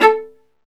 Index of /90_sSampleCDs/Roland L-CD702/VOL-1/STR_Viola Solo/STR_Vla Marcato